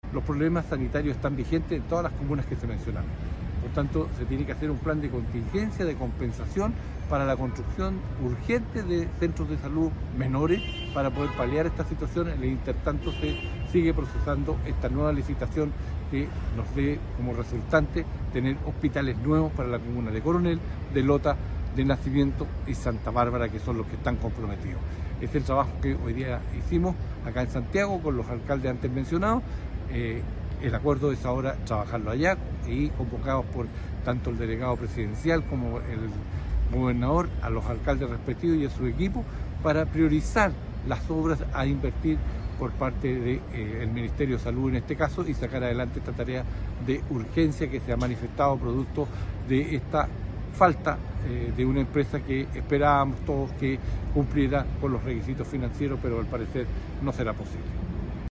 Además, Saavedra recalcó la urgencia de un plan de contingencia por parte del Ministerio de Salud: “Mientras se resuelve el tema de fondo, se debe invertir en centros de salud de menor escala que permitan responder a las necesidades sanitarias actuales de las comunas afectadas”, sostuvo.